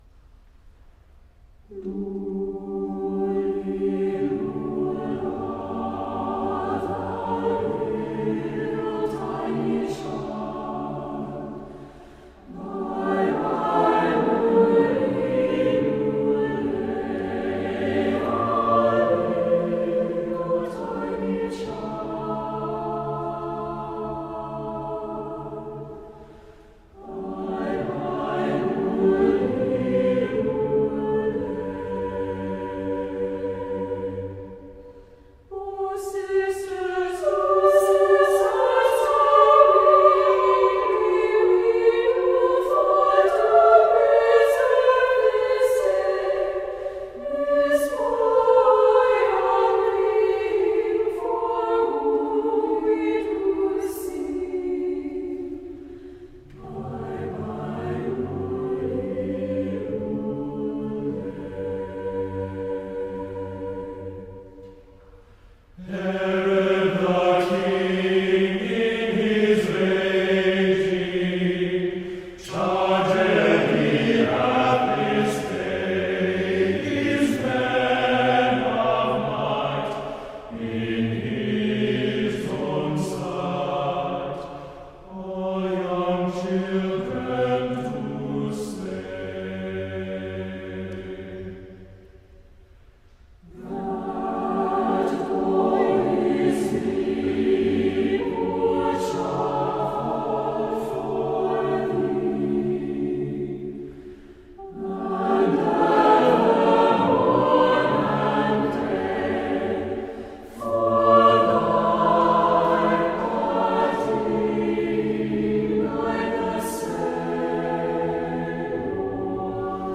This beautiful English lullaby carol originated in the Coventry Corpus Christi Mystery Plays performed in the 15th century.
Choral Music – Sheet Music